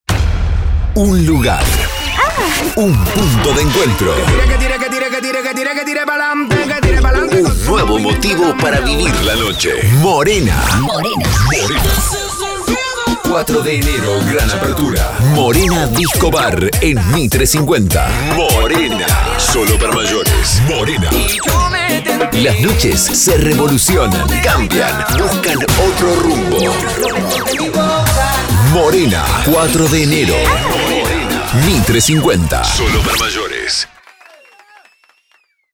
• Spot para boliches, bares y eventos nocturnos. Con edición dinámica.
• ESTILO: GRAVE – BOLICHERO